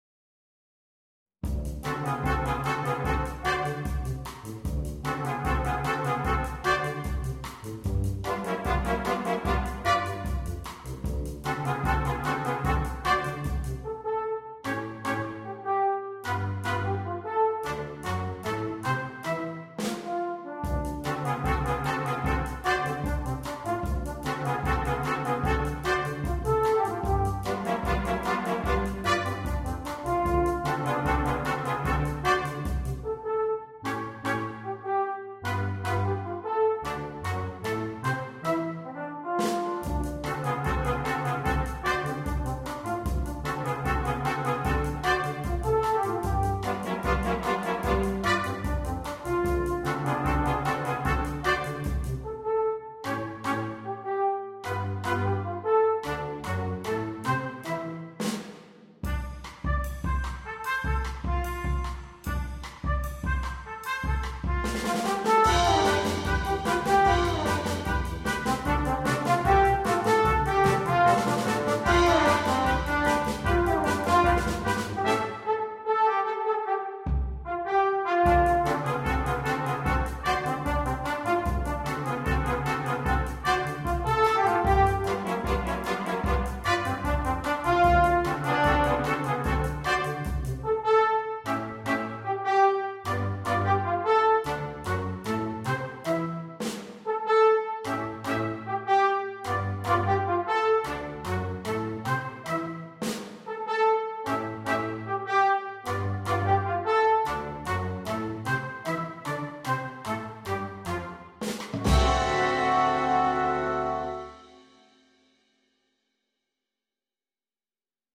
для брасс-бэнда